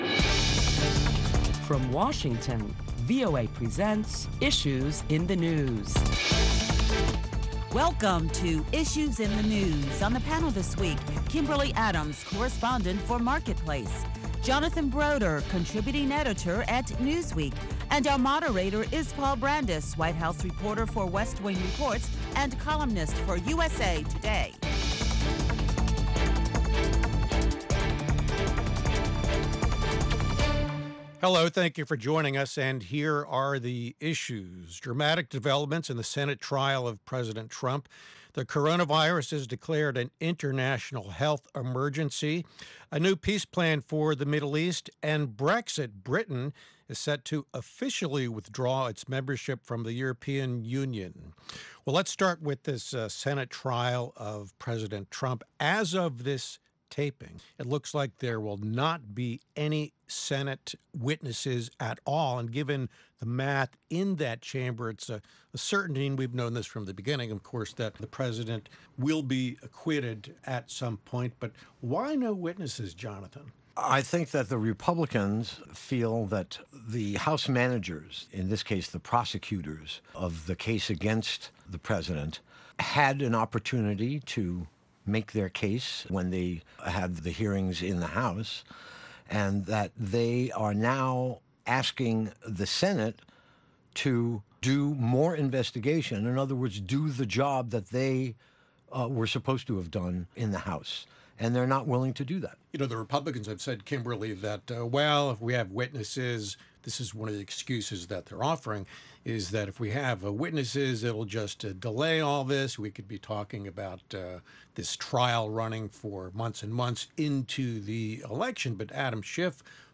Listen to a panel of prominent Washington journalists as they deliberate the latest top stories of the week which will include the latest on the Coronavirus.China says the total number of cases has reached over 7,000.